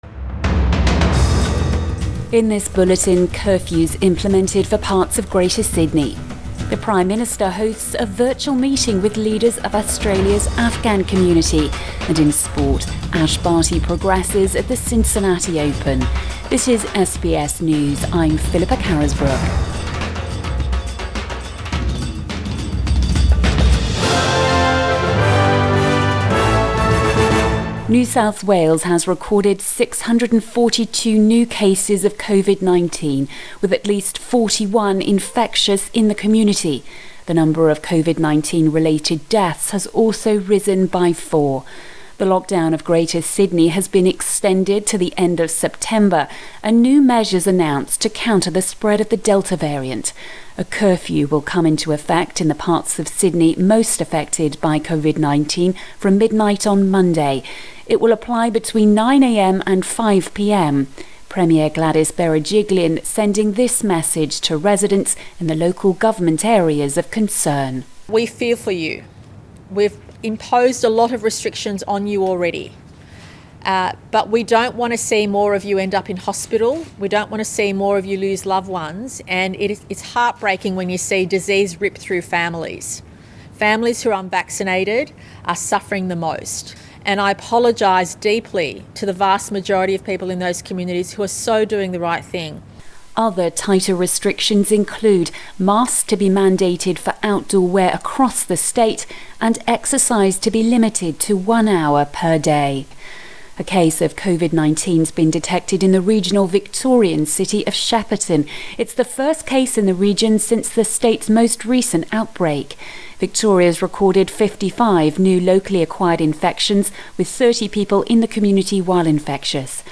Midday bulletin 20 August 2021